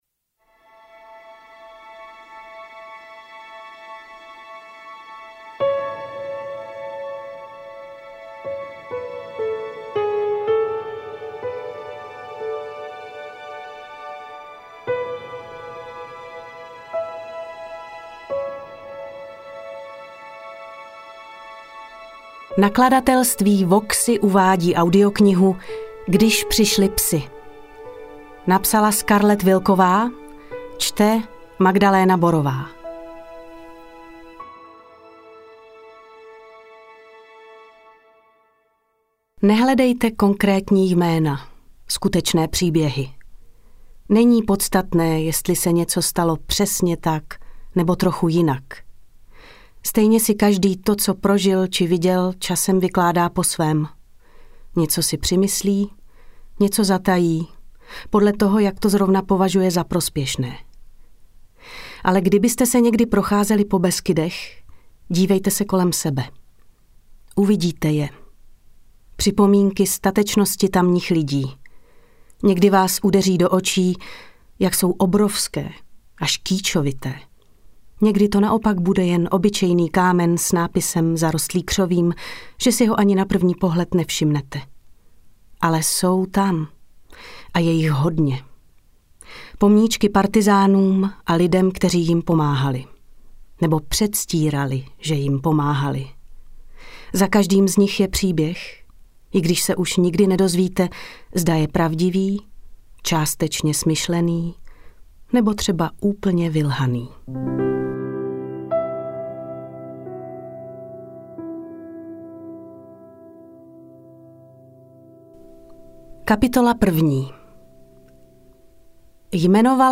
AudioKniha ke stažení, 28 x mp3, délka 9 hod. 2 min., velikost 494,1 MB, česky